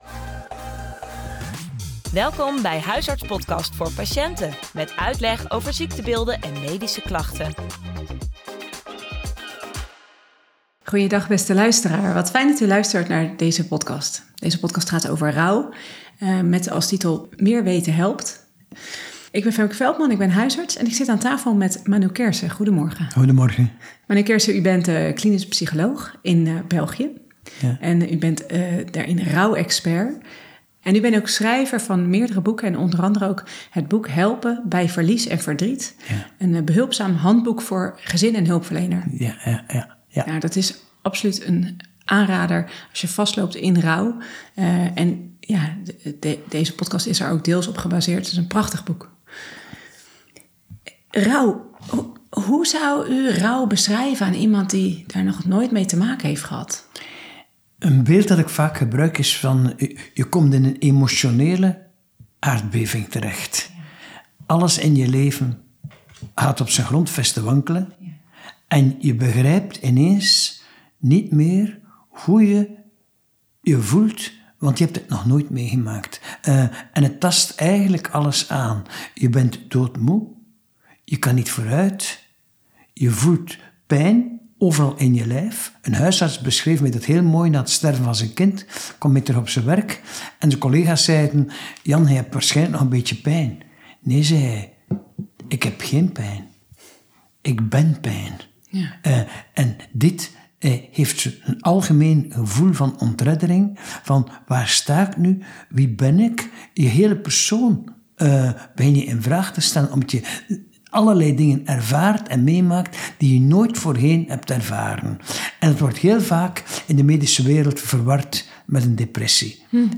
In deze podcast vertelt Manu Keirse (psycholoog) over rouw. Over de pijn, de vermoeidheid en over de golven van verdriet.